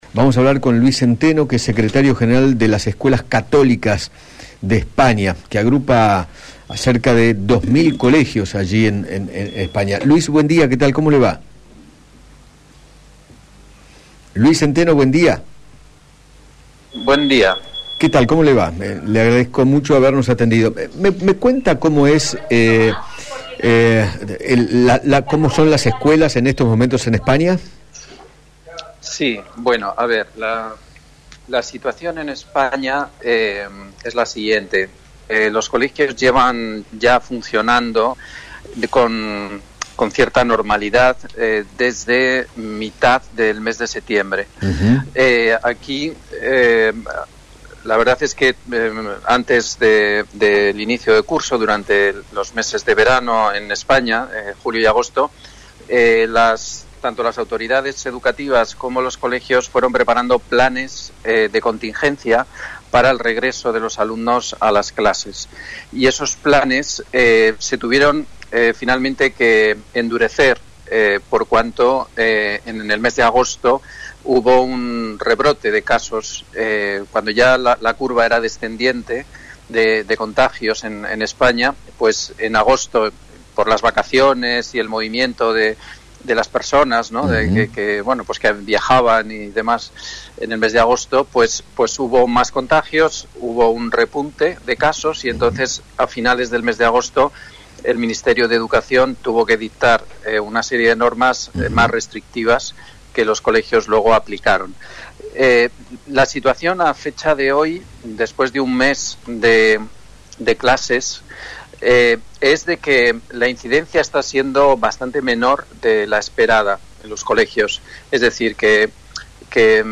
dialogó con Eduardo Feinmann sobre el regreso de las clases en aquel país y sostuvo que “luego de un mes de presencialidad